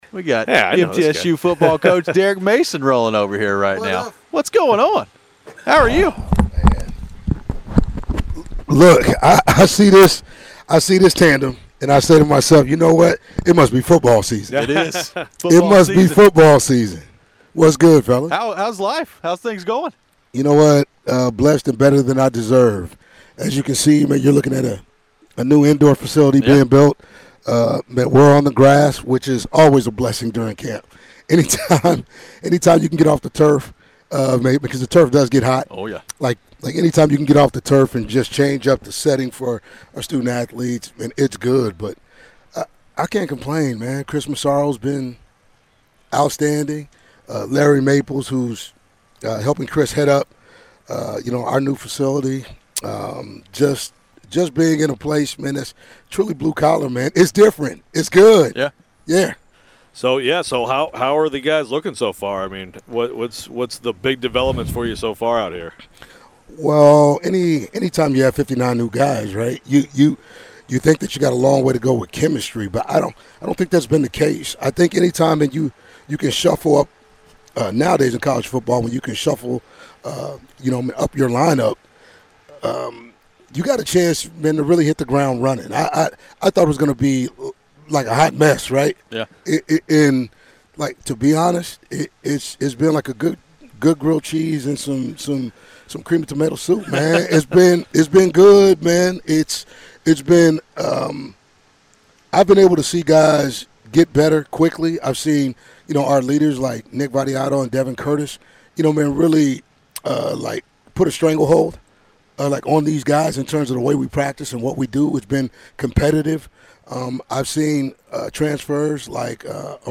Derek Mason Interview (8-6-24)